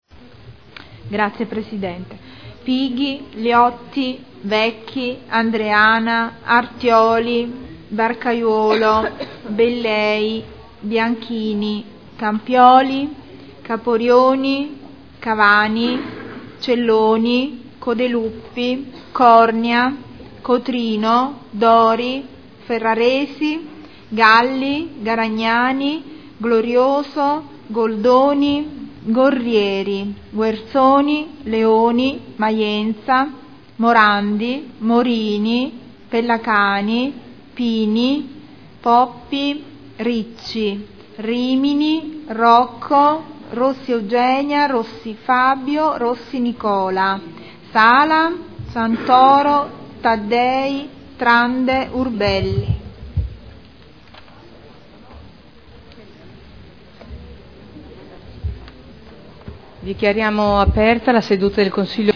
Segretario Generale — Sito Audio Consiglio Comunale
Seduta del 24 febbraio.Appello